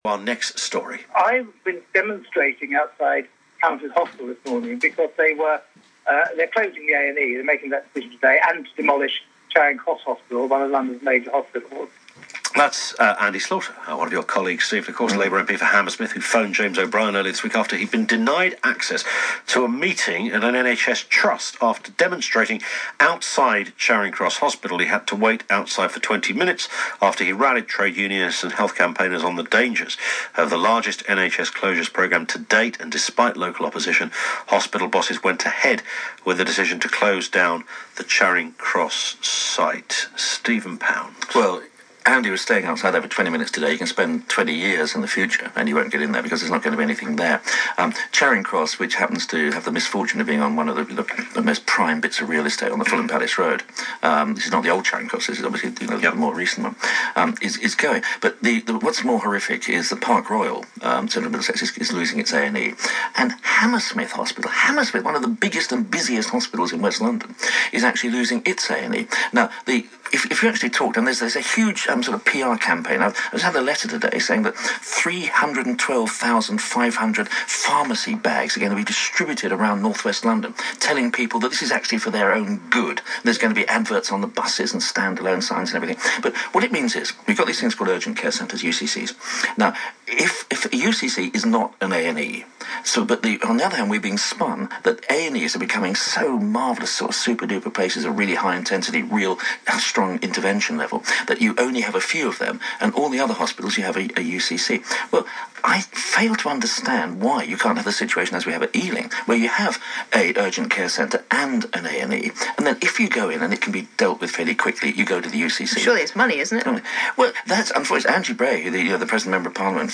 Steve Pound MP, interview on LBC radio